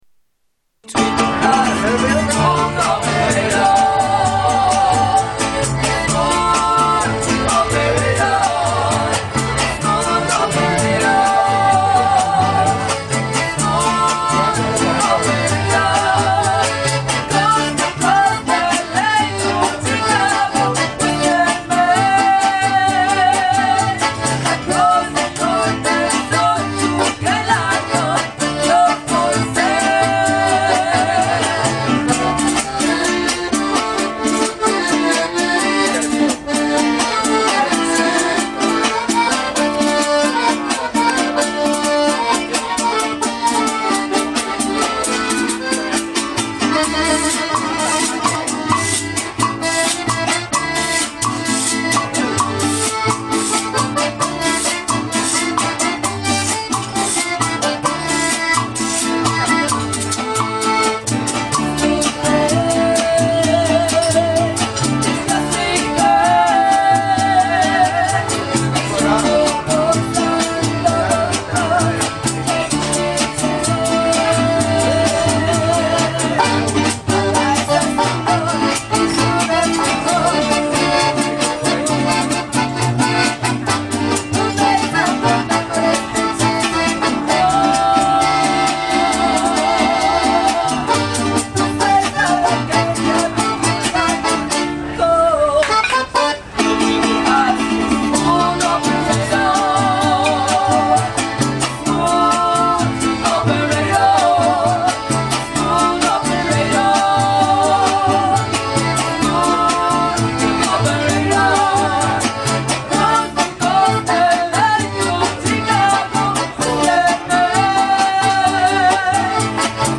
Mariachi Band